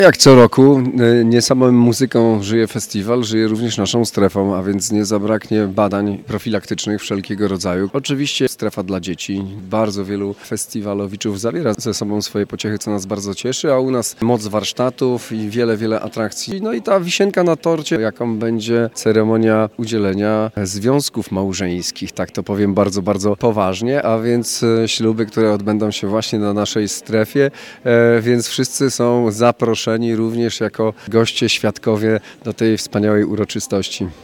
Na festiwalu jest także strefa Pomorza Zachodniego – dodaje marszałek Olgierd Geblewicz